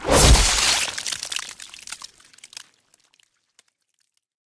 largeblade.ogg